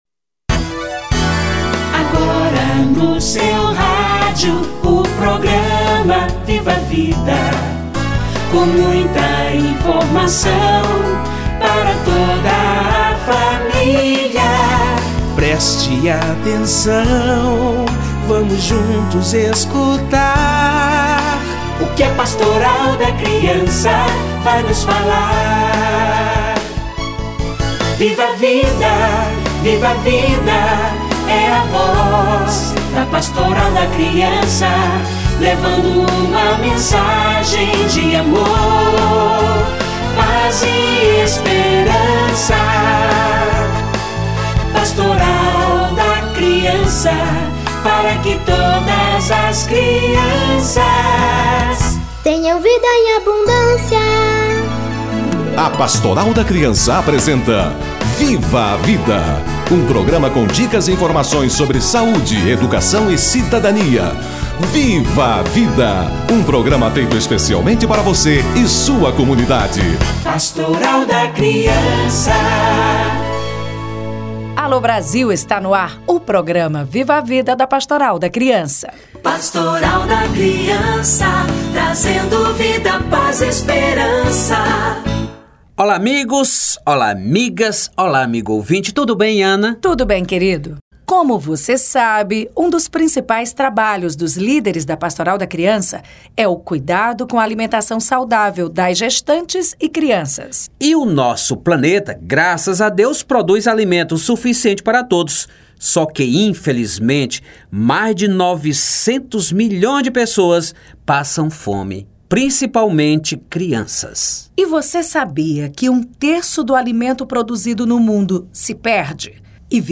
Como evitar o desperdício de alimentos - Entrevista